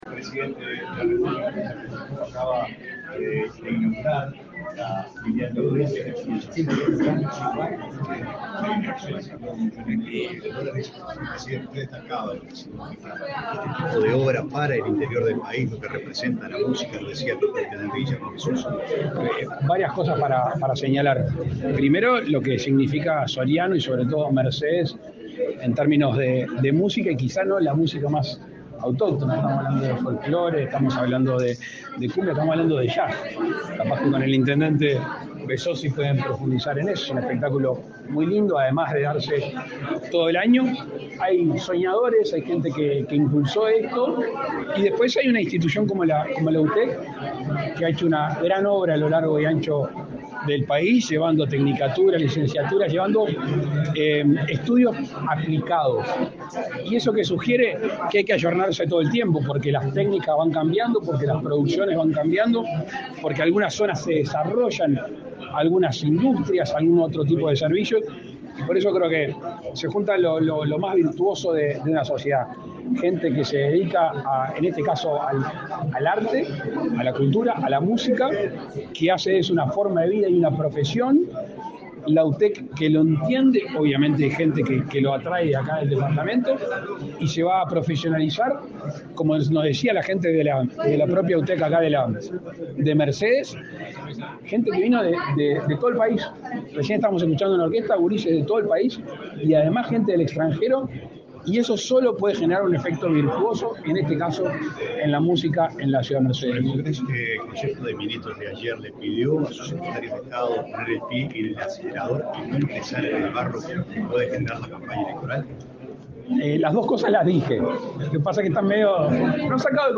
Declaraciones de prensa del presidente de la República, Luis Lacalle Pou
Declaraciones de prensa del presidente de la República, Luis Lacalle Pou 05/06/2024 Compartir Facebook X Copiar enlace WhatsApp LinkedIn Tras participar en la inauguración de obras en la Universidad Tecnológica (UTEC) de Mercedes, en Soriano, este 5 de junio, el presidente de la República, Luis Lacalle Pou, realizó declaraciones a la prensa.